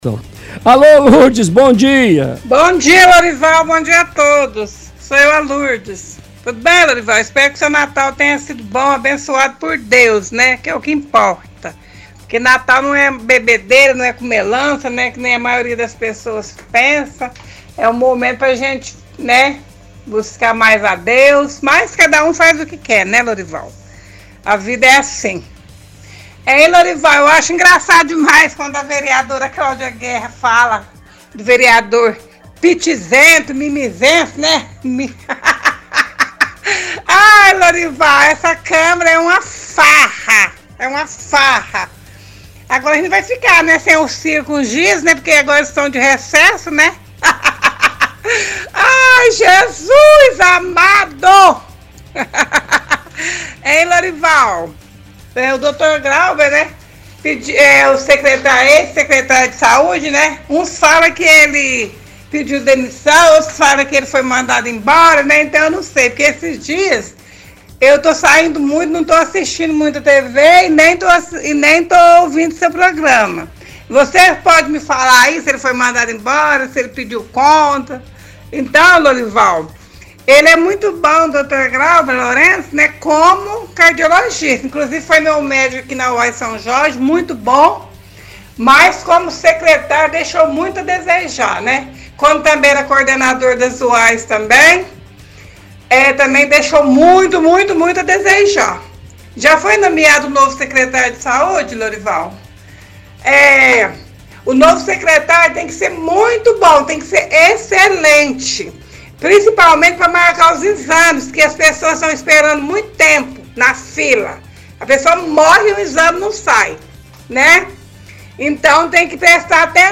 – Ouvinte questiona porque Clauber foi exonerado, afirmando que ele era muito bom como cardiologista, mas não como secretário.